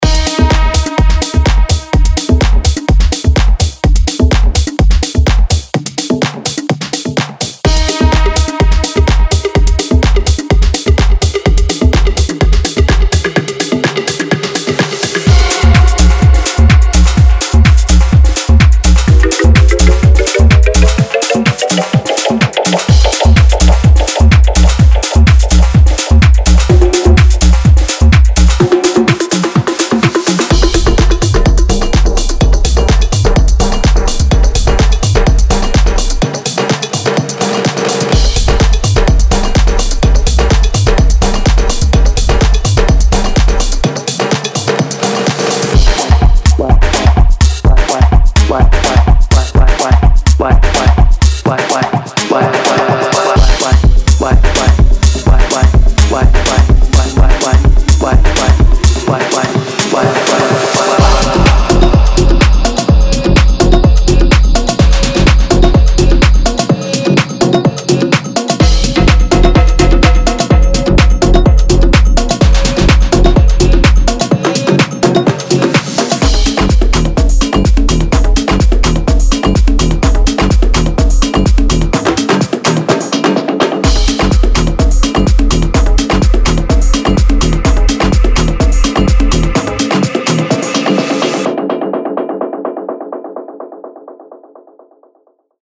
•31 x Full Drum Loops
•33 x Bass Loops
•34 x Synth Loops